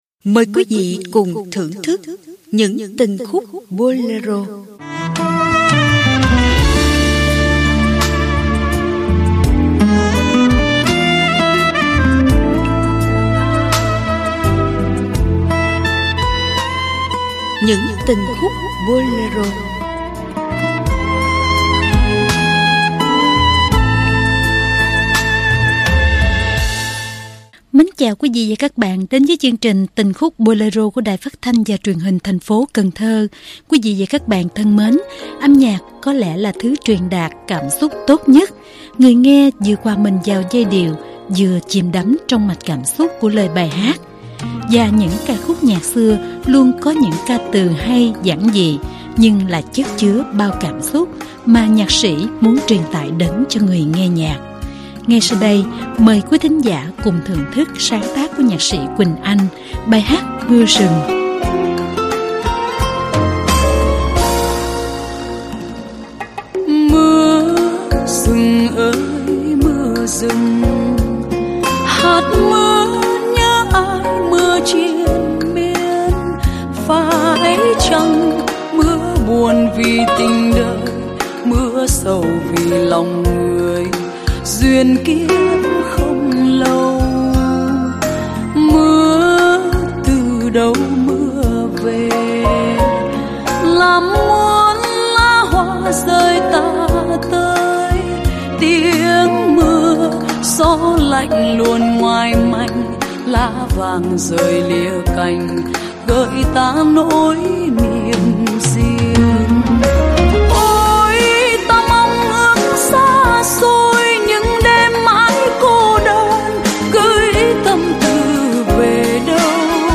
Tình khúc Bolero